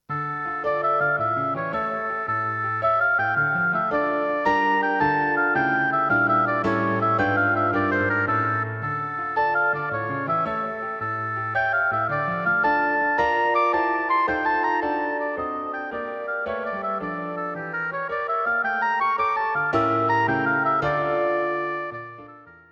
The audio clip is the third movement - Allegretto.